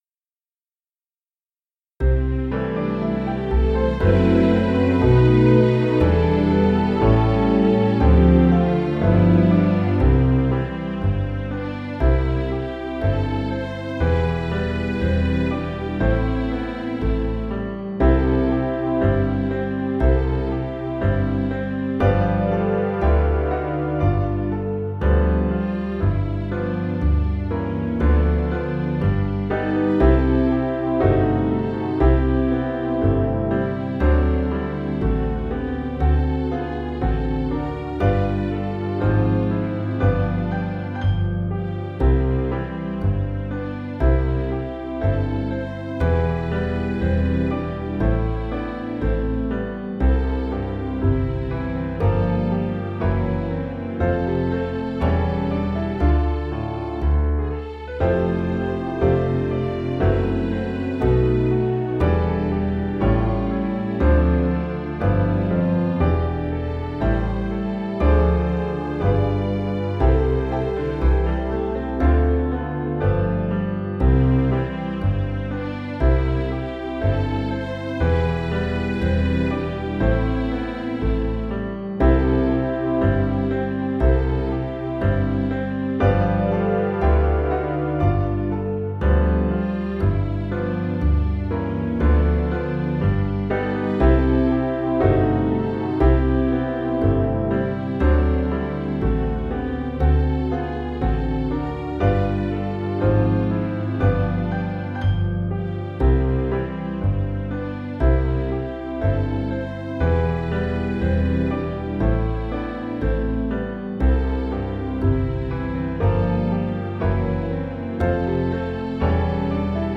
rehearsal recording